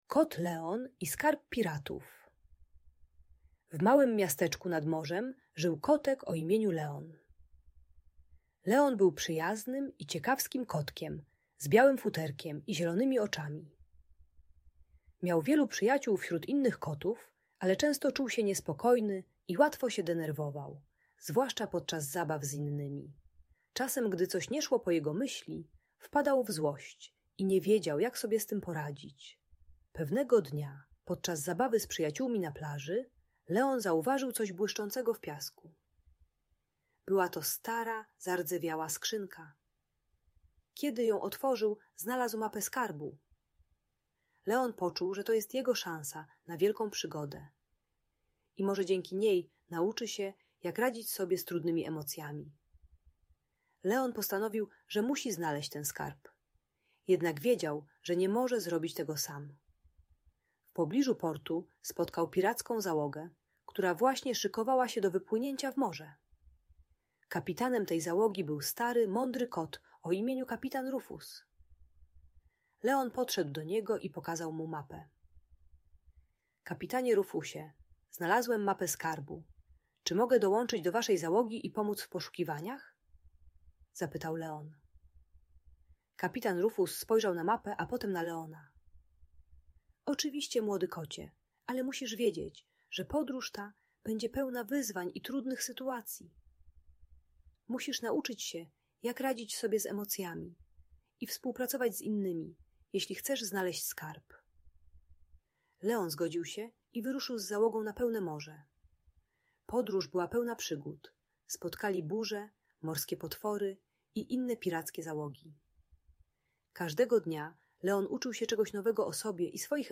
Kot Leon i Skarb Piratów - Audiobajka